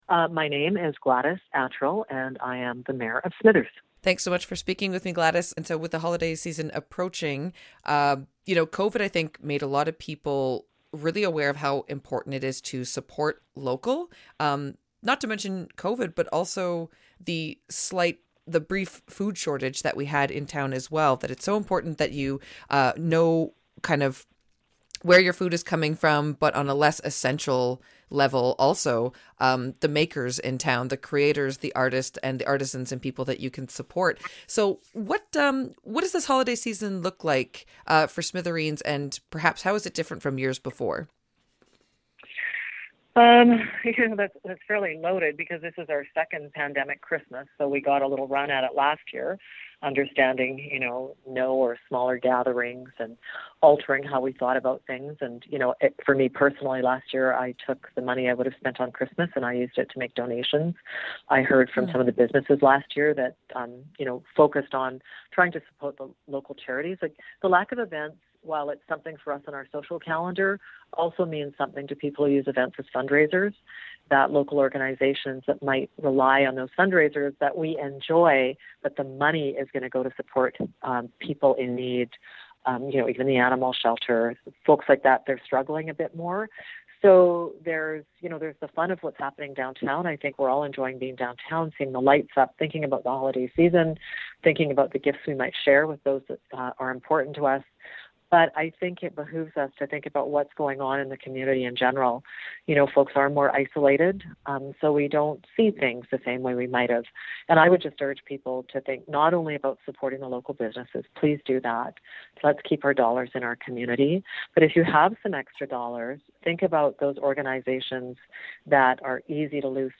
Listen below to CICK's interview with Smithers Mayor Gladys Atrill: Download Audio Prev Previous Post After the first snow: looking at local weather trends for this winter Next Post Third annual community Christmas dinner a success Next